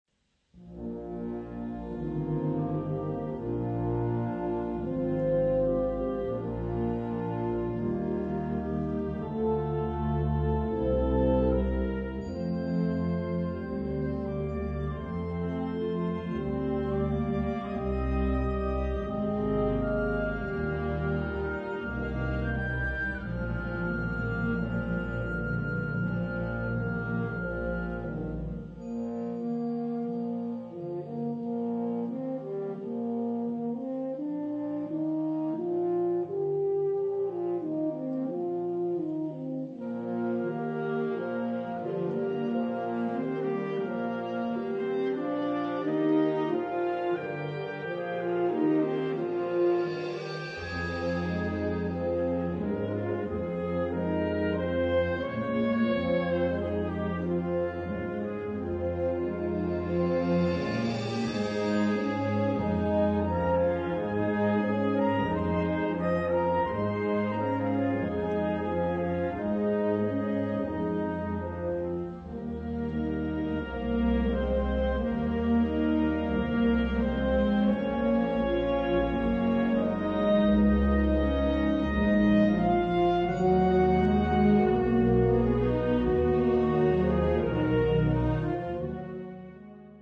Bezetting Ha (harmonieorkest)